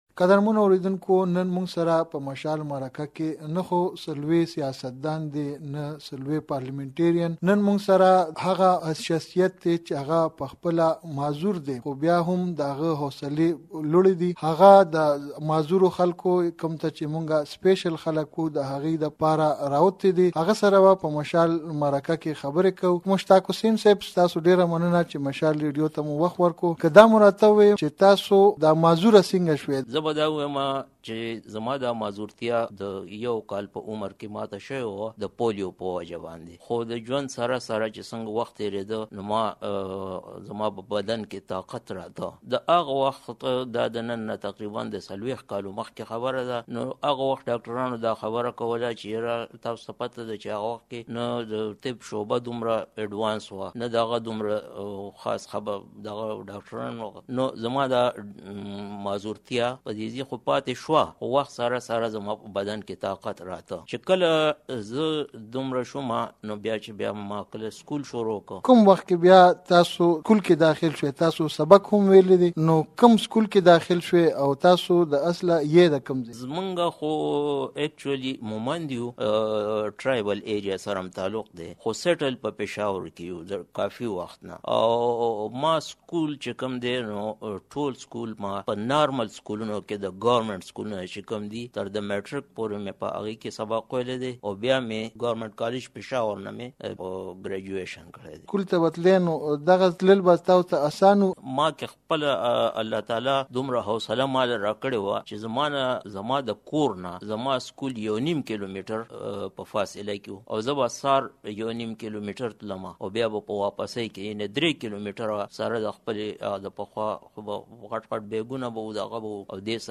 مركه